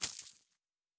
pgs/Assets/Audio/Fantasy Interface Sounds/Coins 01.wav at master
Coins 01.wav